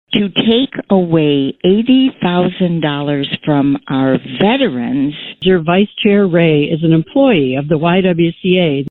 KALAMAZOO, MI (WKZO AM/FM) – Abortion was on the national agenda on election day in several states and Wednesday night it became a point of contention at the Kalamazoo County board meeting.